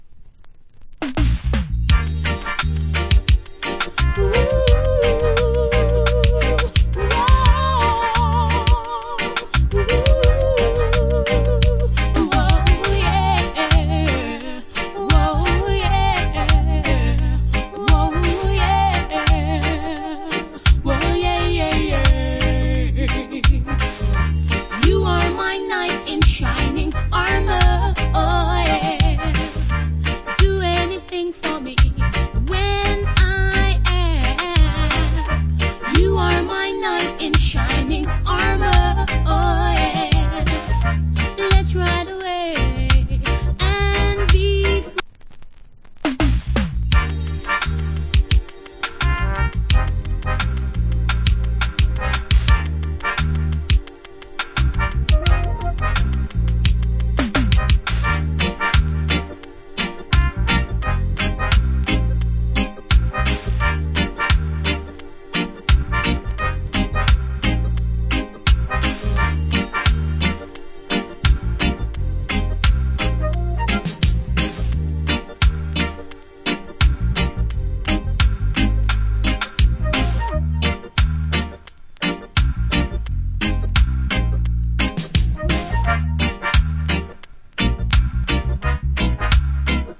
80's. Cool Female UK Lovers.